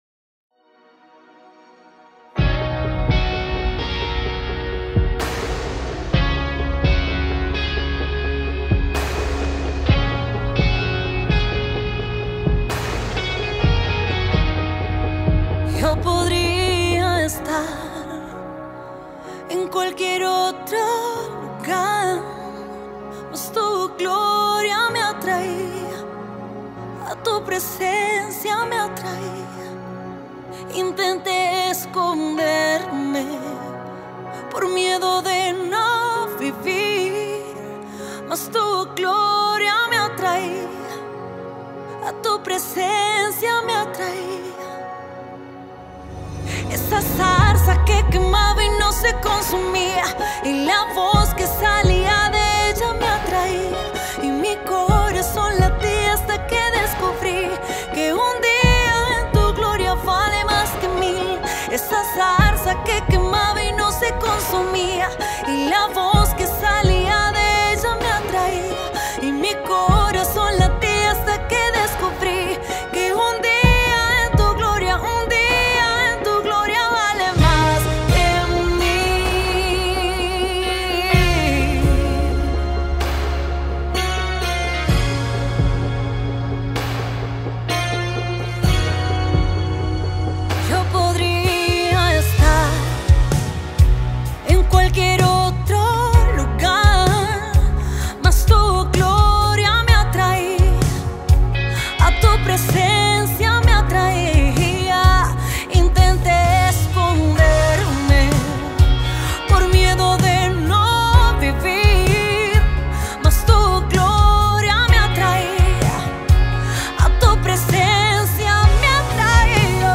Universal Gospel
The soulful gospel music singer